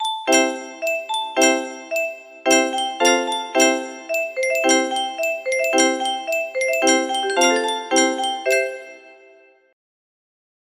Laterne, Laterne 🏮 music box melody
German children song